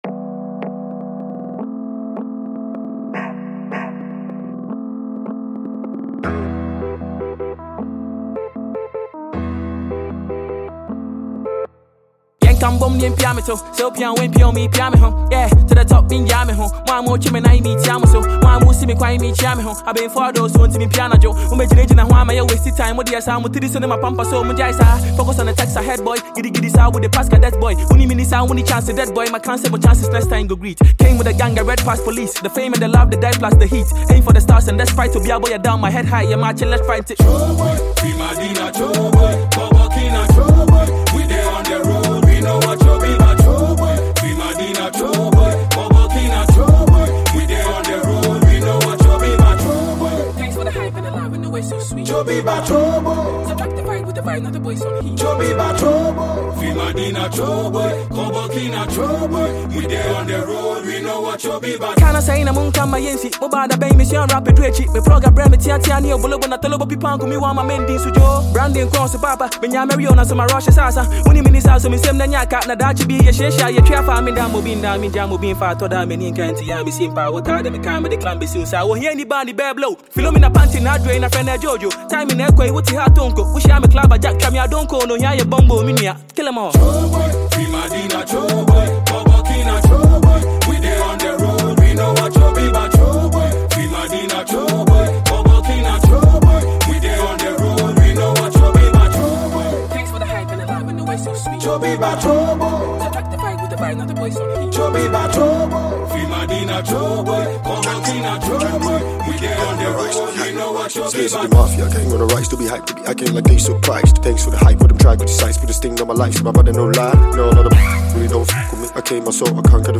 Ghanaian multi-skilled rapper